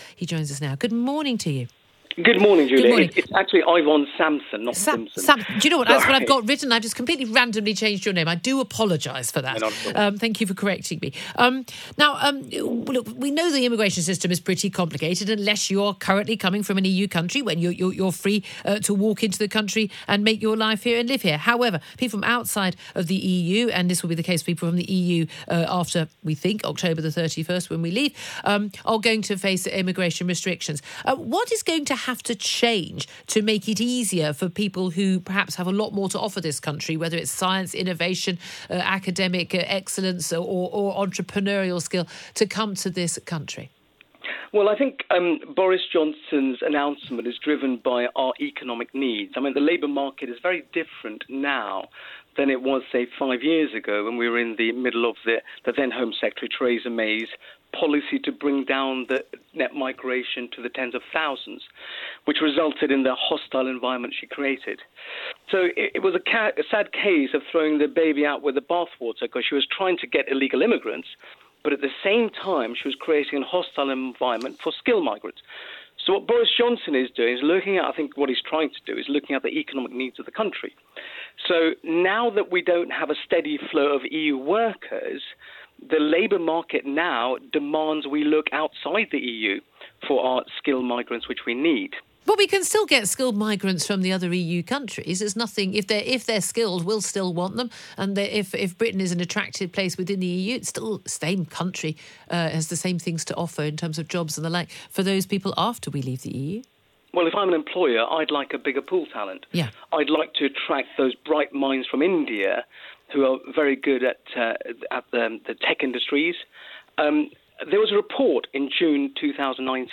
Listen to the full radio interview here: https